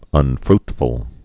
(ŭn-frtfəl)